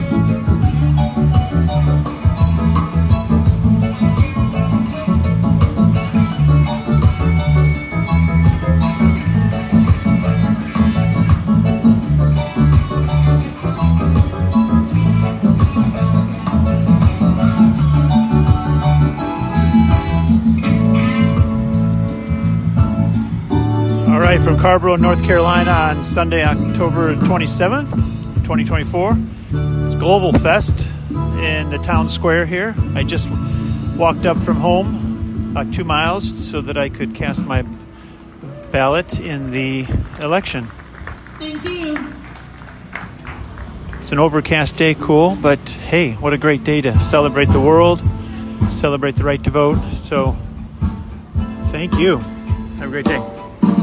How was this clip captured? Microcast from Carrboro Town Plaza just after I cast my ballot.